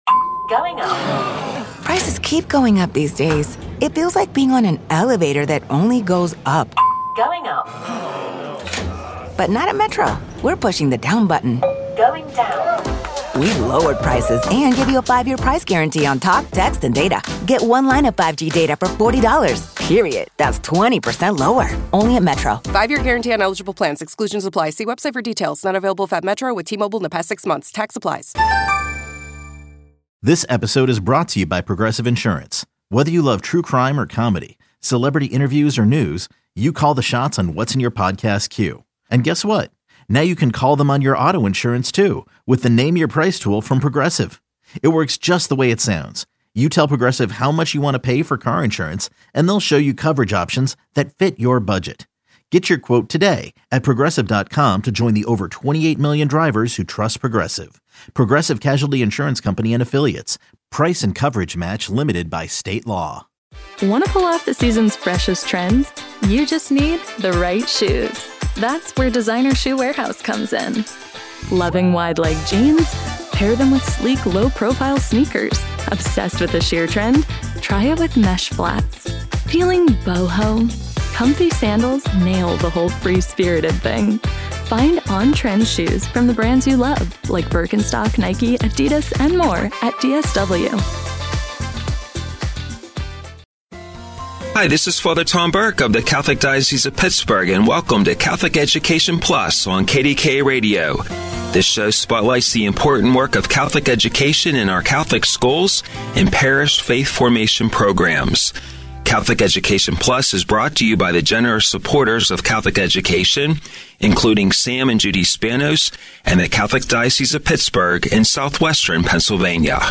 A conversation with Crossroads Foundation, which helps promising young people receive a quality Catholic high school education and be prepared to succeed in college and in life.